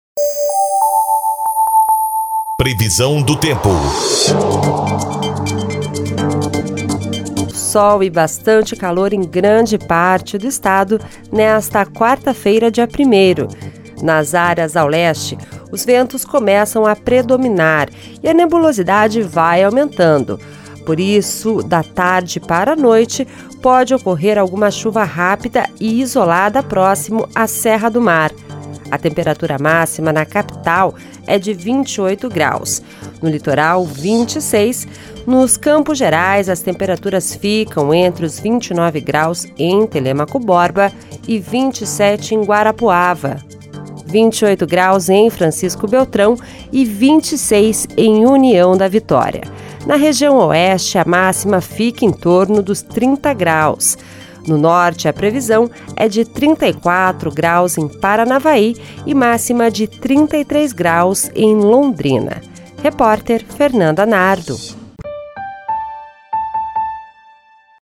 Previsão do Tempo (01.12)